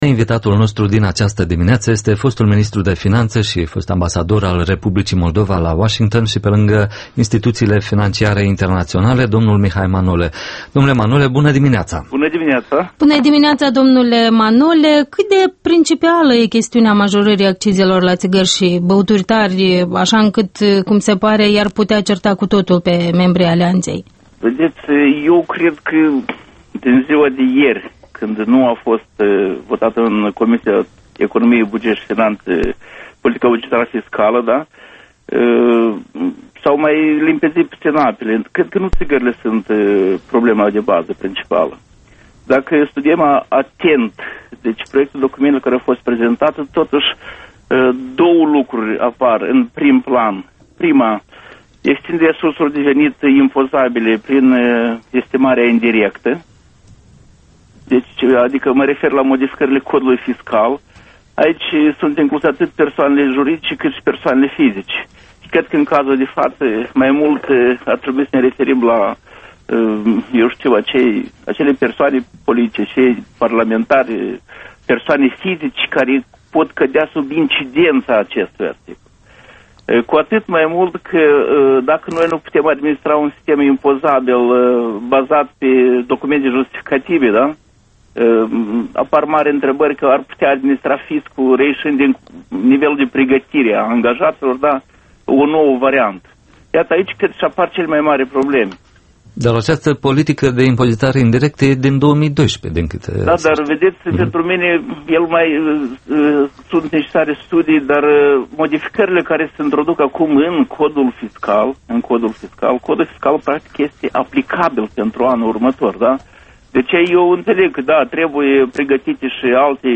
Interviul matinal EL: